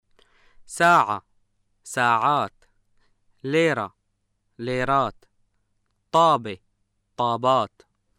[saaʕa (saaʕaat), leera (leeraat), Taabe (Taabaat)]